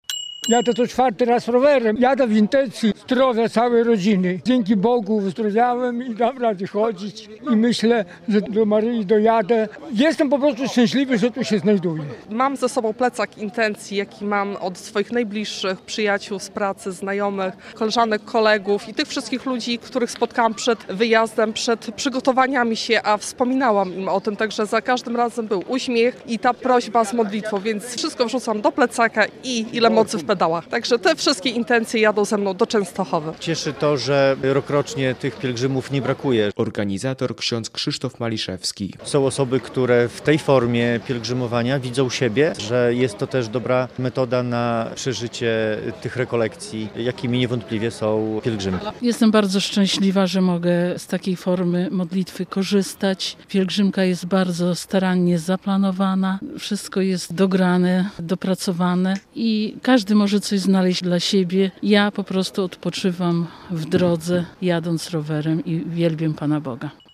W sobotę (3.08) rano spod białostockiej Katedry nieco ponad 130 rowerzystów wyjechało na pielgrzymkę.
Jestem na "Rowerymce" po raz czwarty i jadę w intencji całej rodziny. Jestem bardzo szczęśliwy, że się tutaj znajduję - mówił jeden z pielgrzymów.